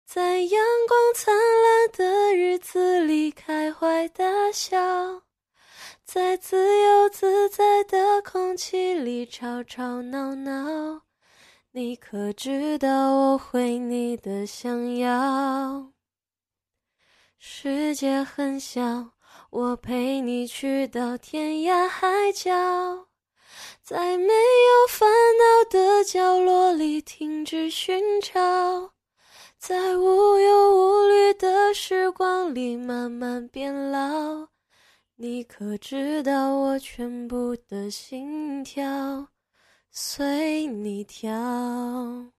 M4R铃声, MP3铃声, 华语歌曲 25 首发日期：2018-05-14 13:31 星期一